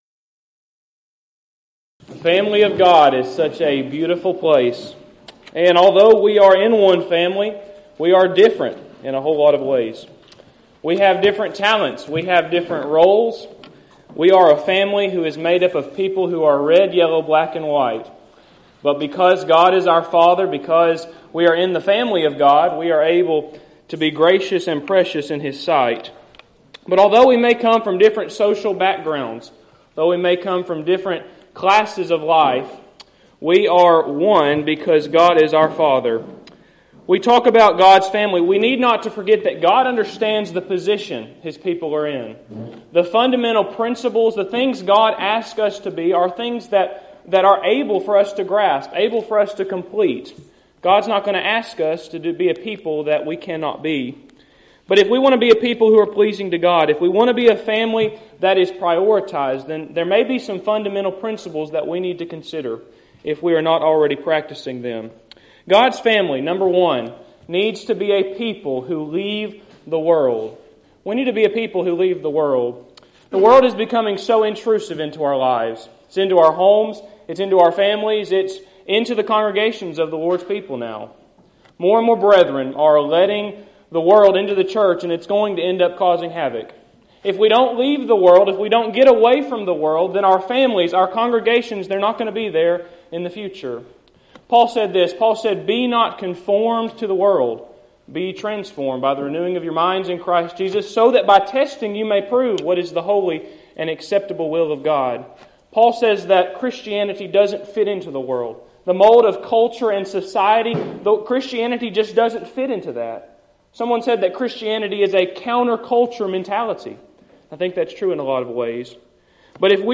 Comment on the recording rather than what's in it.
Event: 3rd Annual Southwest Spritual Growth Workshop